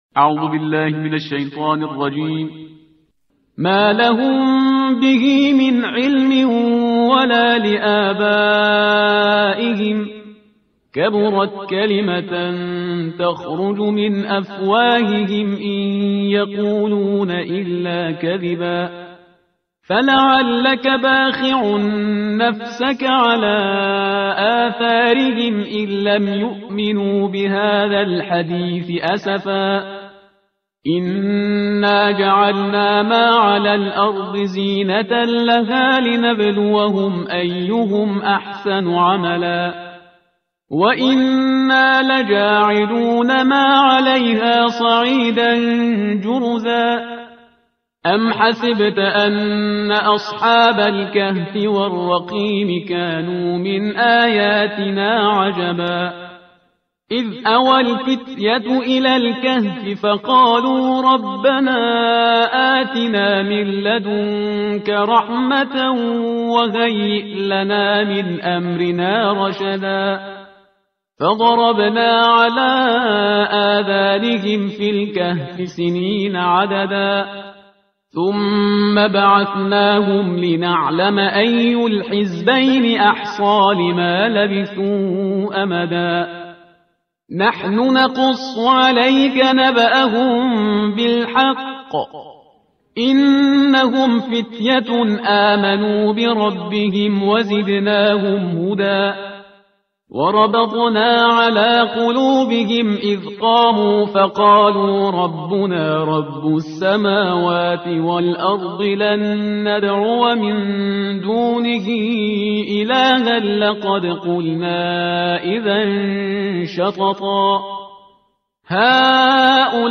ترتیل صفحه 294 قرآن